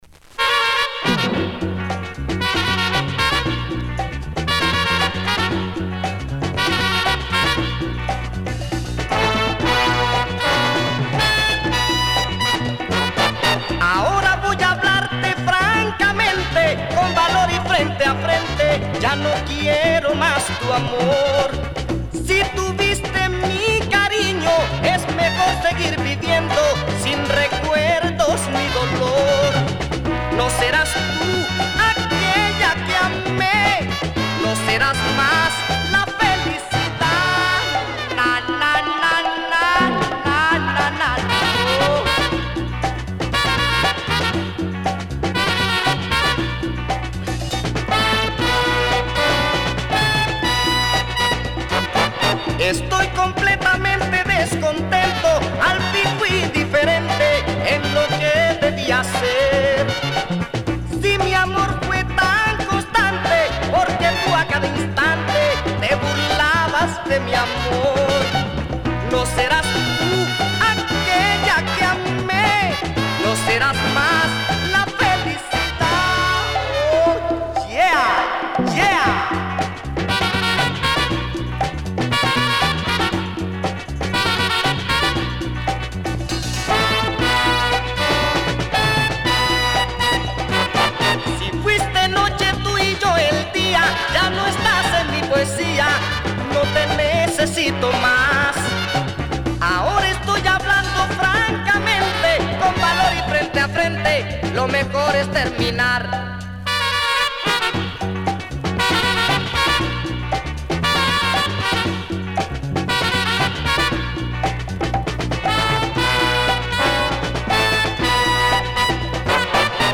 Published September 29, 2010 Boleros y Baladas Comments
over-the-top fuzzed out lyrical romanticism